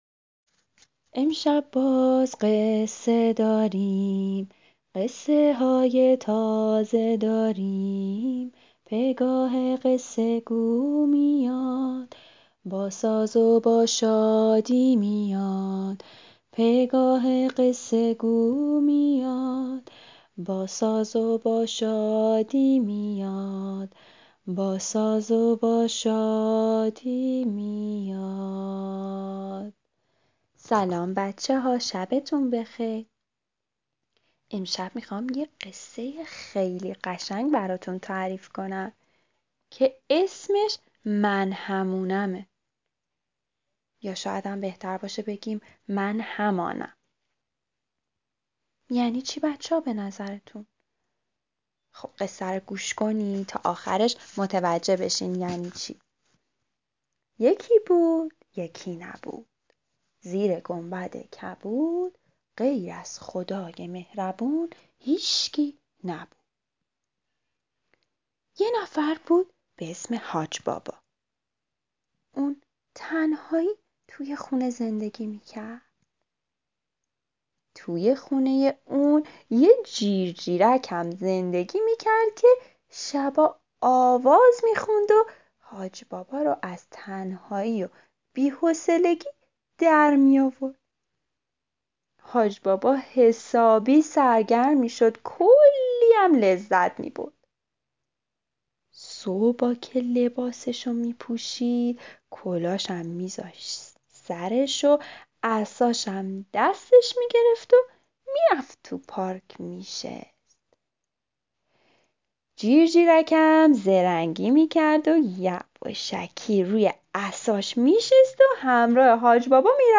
قصه صوتی کودکان دیدگاه شما 3,076 بازدید